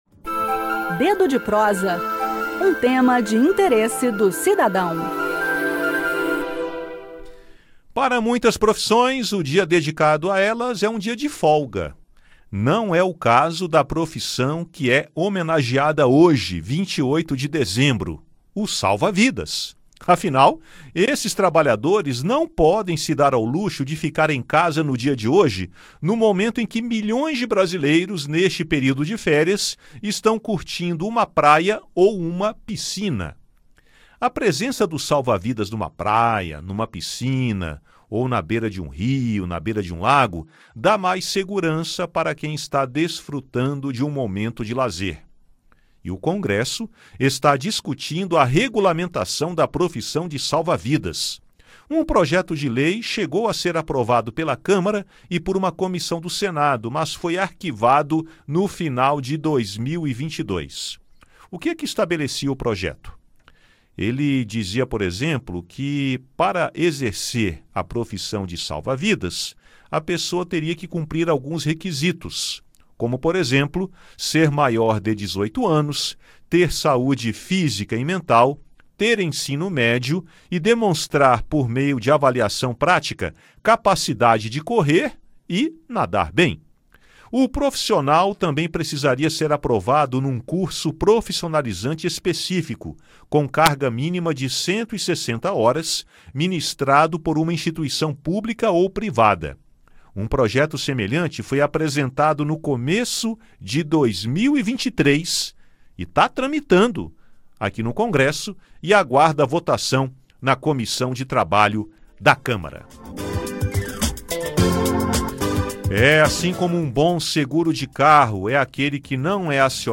Ouça no bate-papo a nossa homenagem aos salva-vidas.